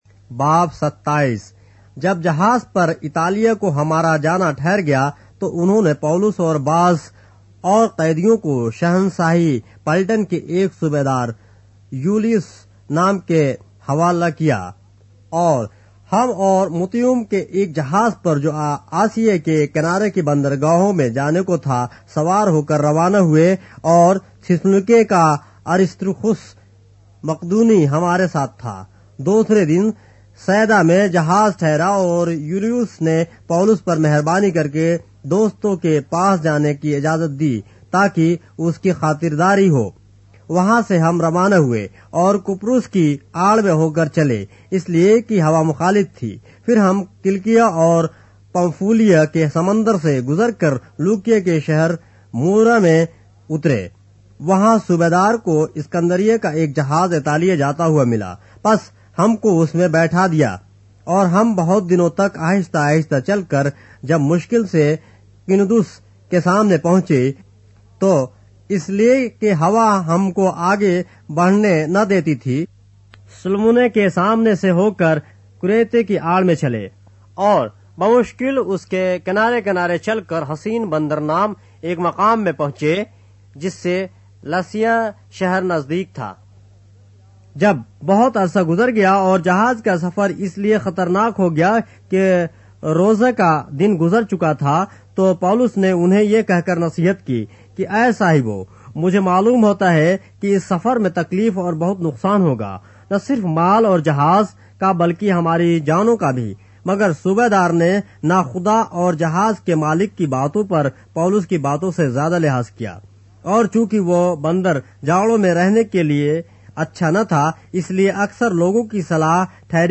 اردو بائبل کے باب - آڈیو روایت کے ساتھ - Acts, chapter 27 of the Holy Bible in Urdu